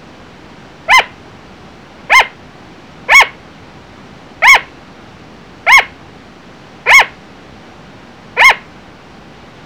仲間を呼ぶ1
113kb   仲間を呼ぶ１ 寝込みを襲う声。
（ケージのある部屋を明るくすると鳴きやむ。ごめんね）   アンアン
anan.wav